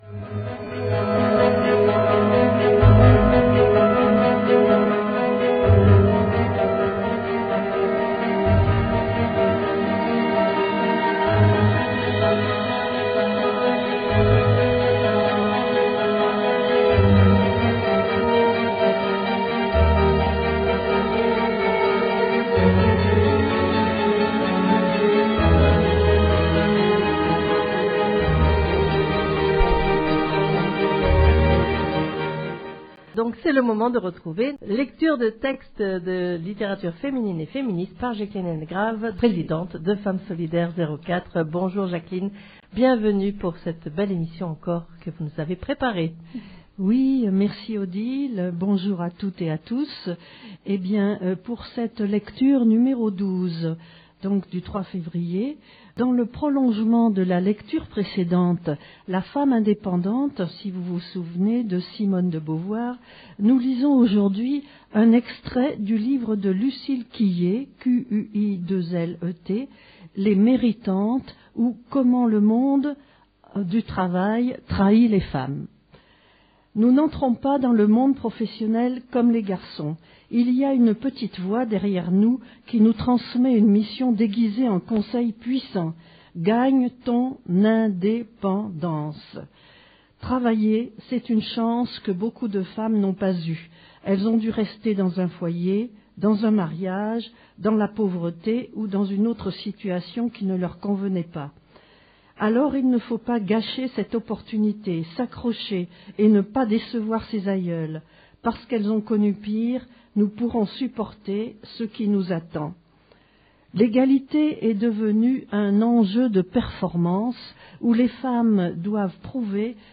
Lecture de textes de littérature féminine et féministe N°12